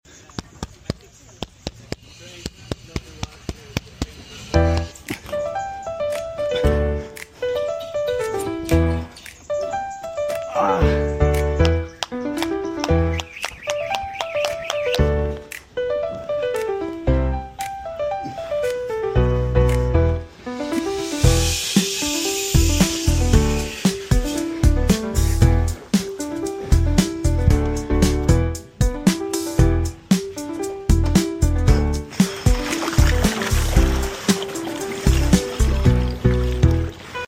I created a beat using just a punching bag and turned it into a full song effortlessly. No instruments needed, just creativity and tech.
Process: 1. Record sounds from a punching bag with Ray-Ban Meta. 2. Use Suno to turn those sounds into a song. 3.